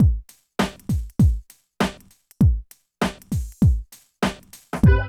70 DRUM LP-R.wav